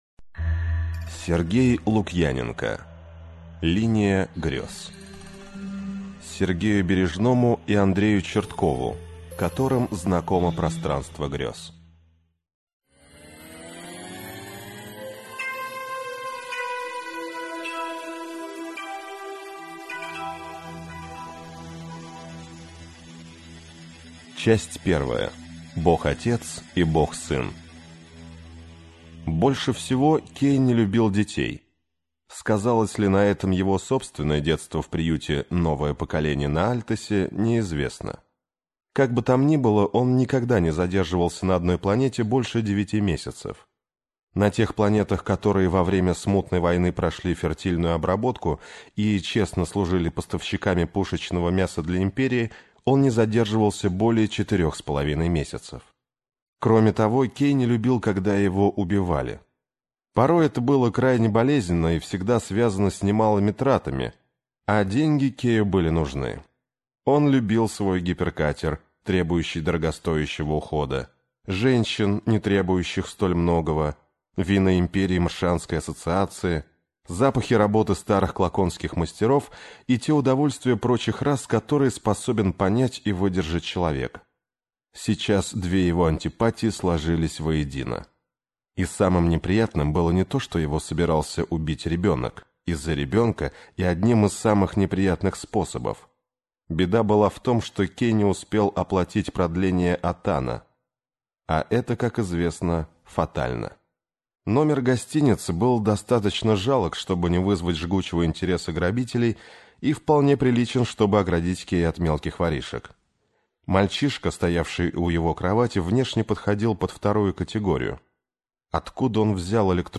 Аудиокнига Линия Грез - купить, скачать и слушать онлайн | КнигоПоиск